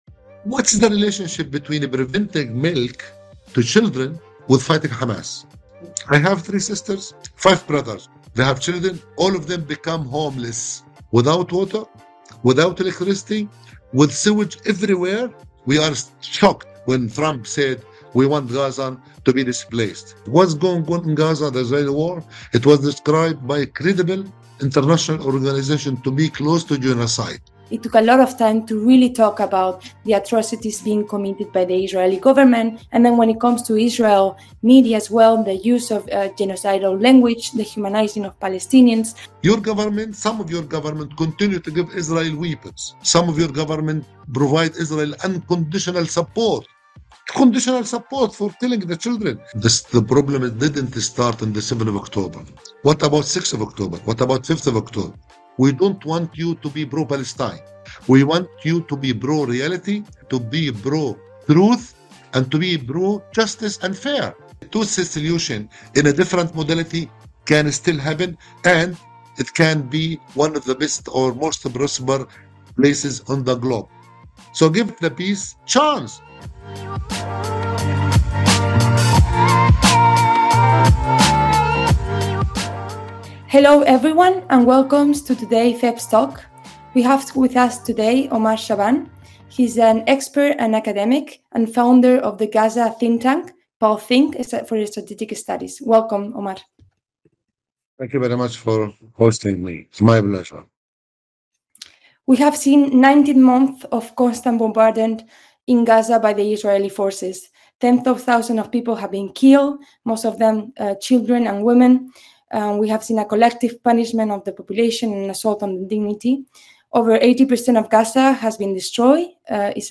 This podcast was recorded just a few hours after the polling stations across the EU closed. It features Matthias Ecke, a returning Member of the European Parliament from SPD in Germany.